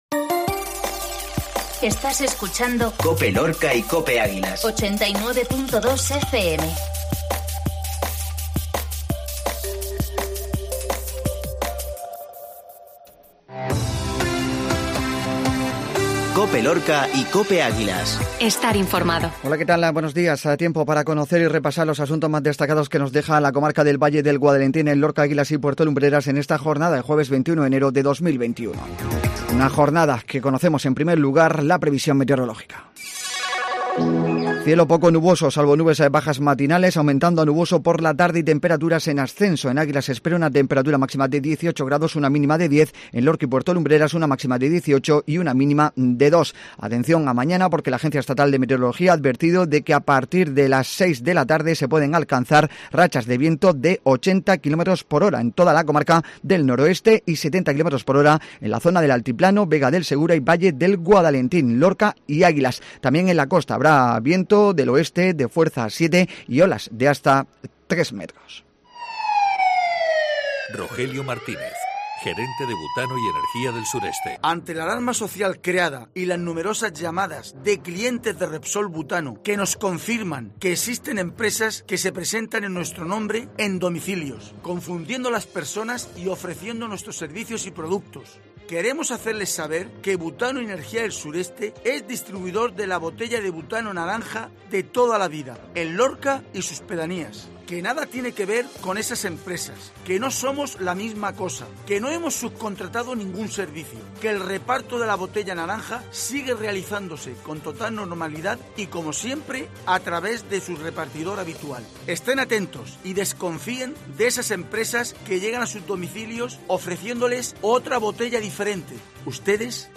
INFORMATIVO MATINAL COPE LORCA